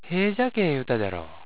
ためになる広島の方言辞典 は．
hejya.wav